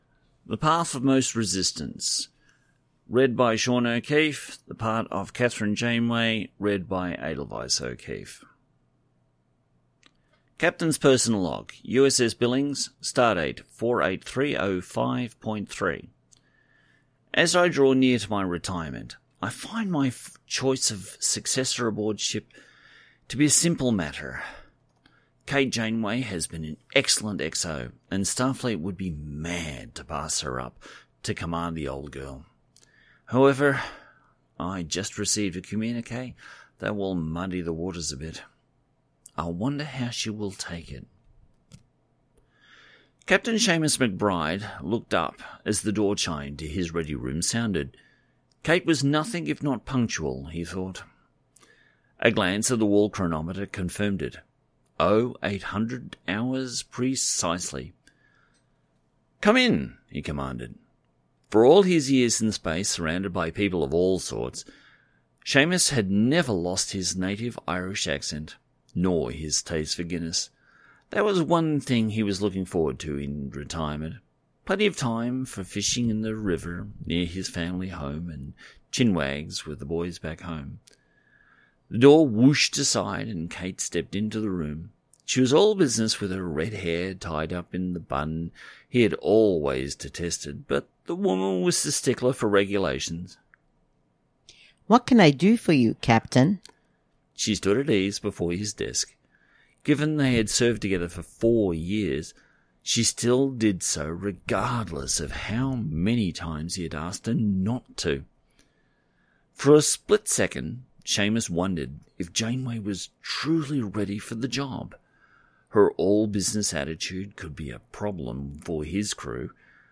Audio Books/Drama Author(s